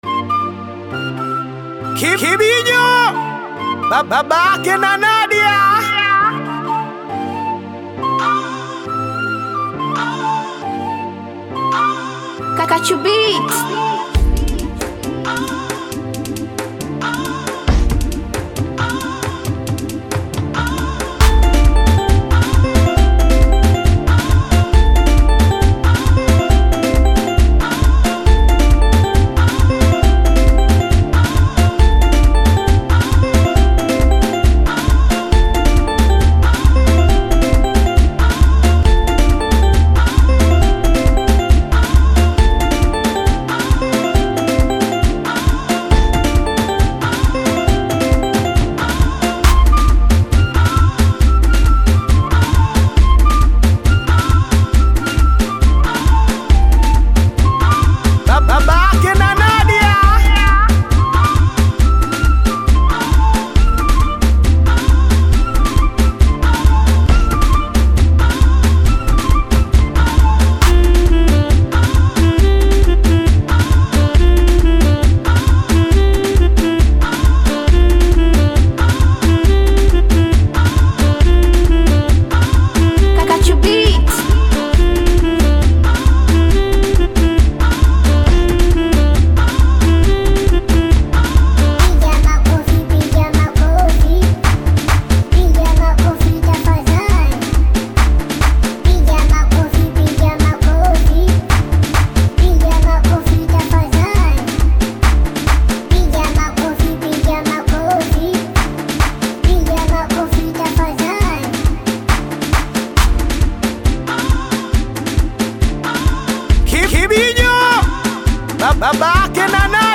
DOWNLOAD BEAT SINGELI